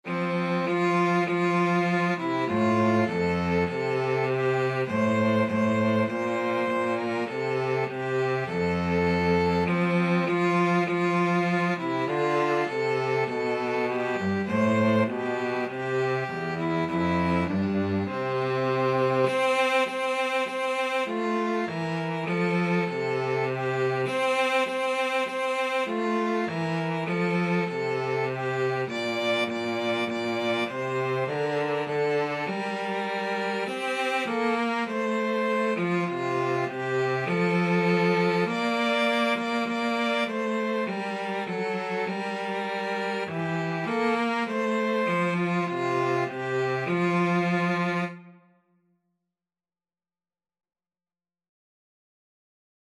4/4 (View more 4/4 Music)
Classical (View more Classical Violin-Cello Duet Music)